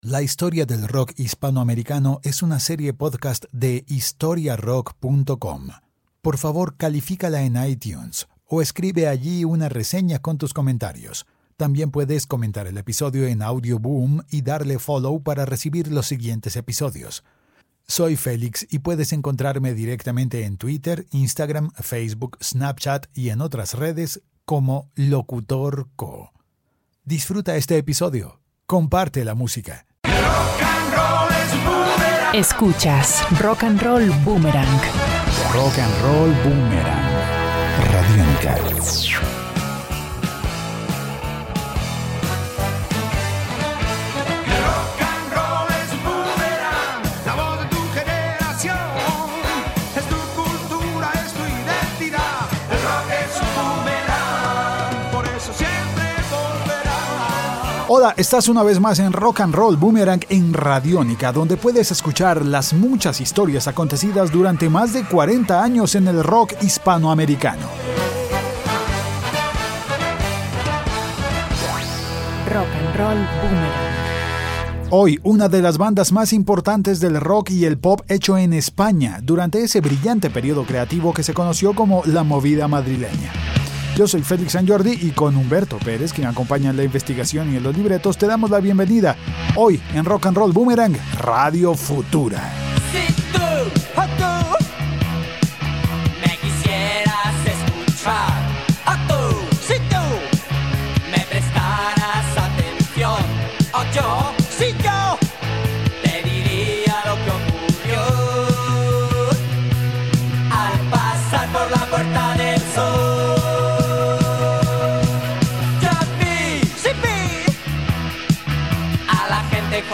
Canción 1: Enamorado de la moda juvenil Canción 2: La estatua del jardín botánico